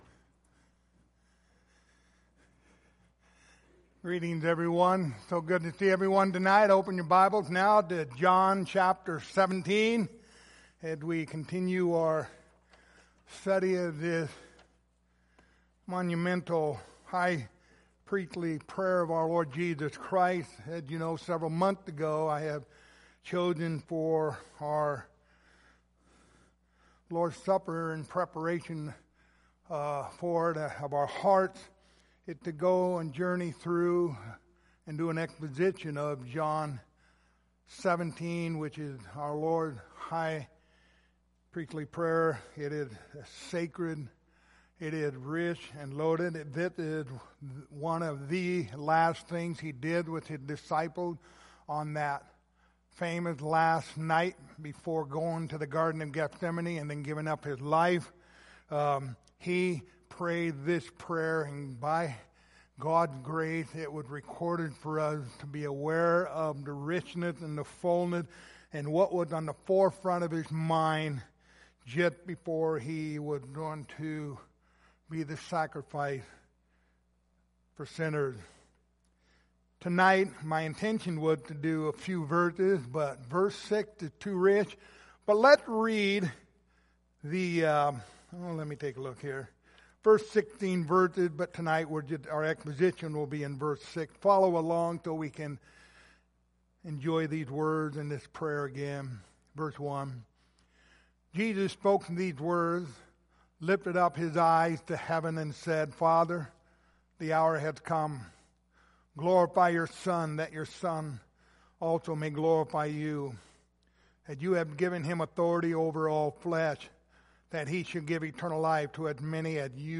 Passage: John 17:6 Service Type: Lord's Supper